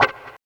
134 GTR 3 -R.wav